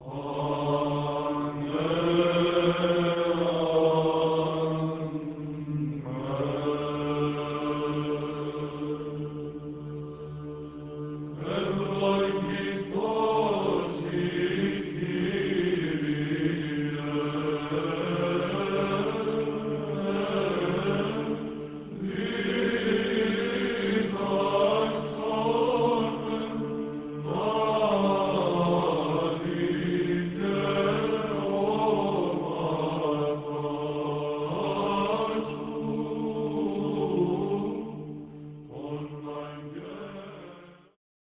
was performed on June 1998 by the University Byzantine Choir
5. Eulogetaria slow, Plagal First mode